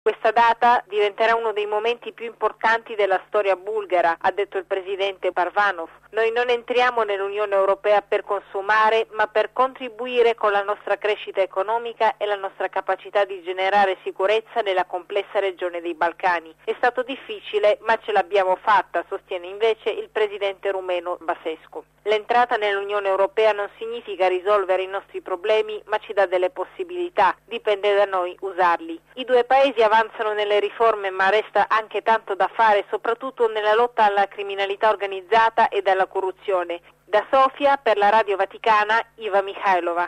da Sofia